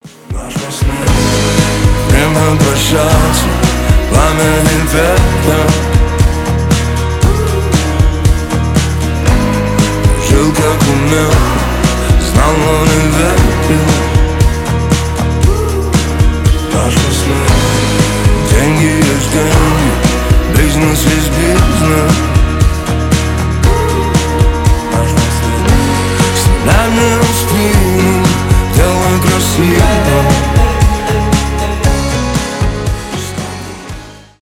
лирика
поп
рэп